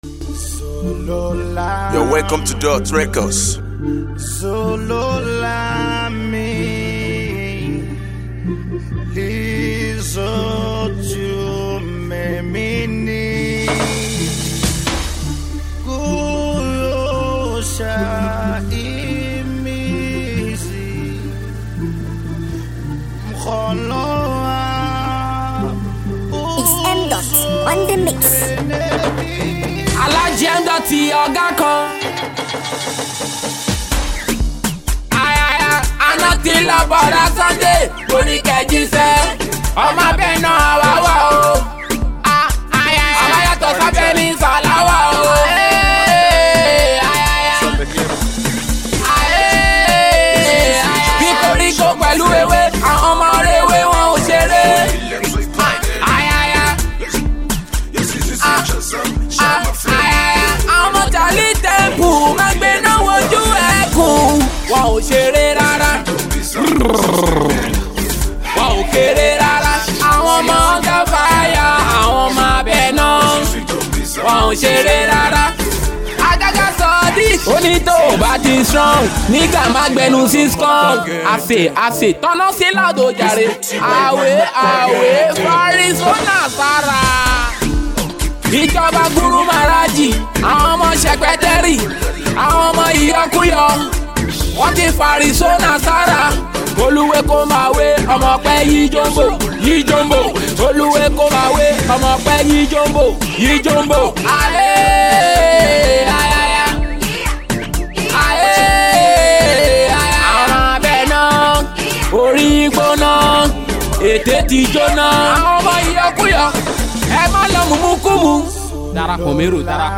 dance hall song